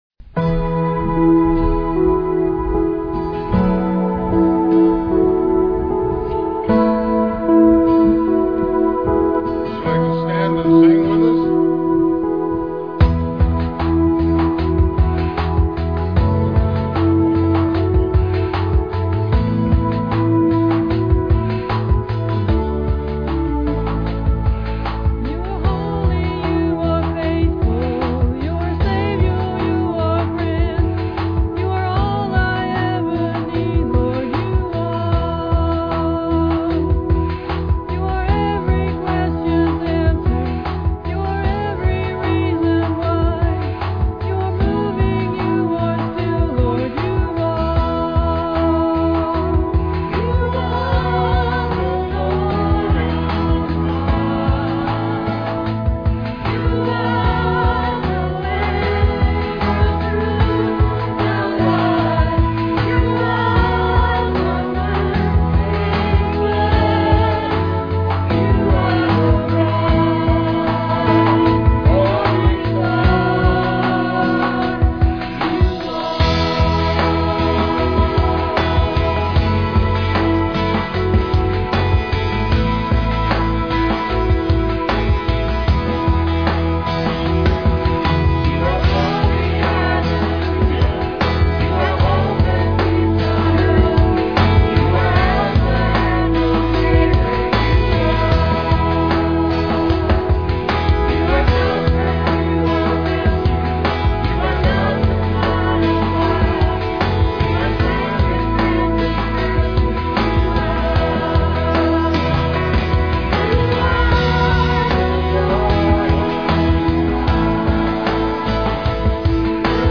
PLAY Man of Miracles, Part 6, Aug 13, 2006 Scripture: Mark 6:1-10. Scripture Reading